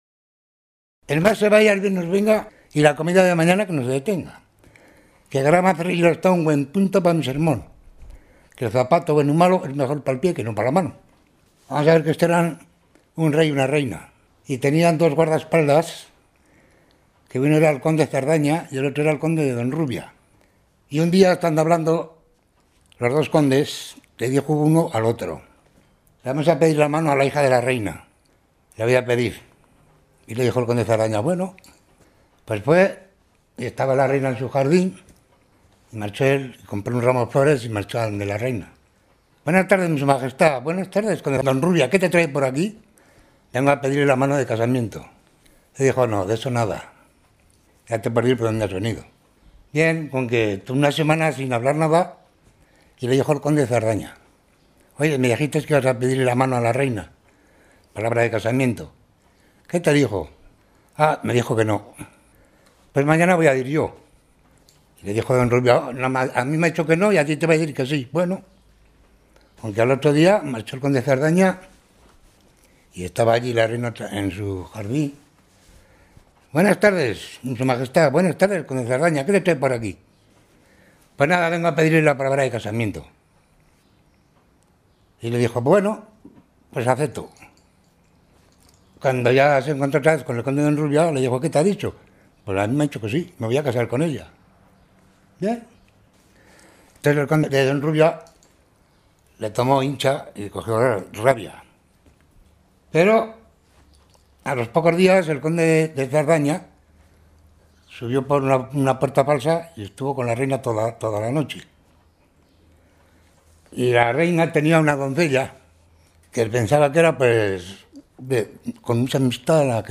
Clasificación: Relato épico, romancero
Lugar y fecha de recogida: Estella (Navarra), 31 de mayo de 2006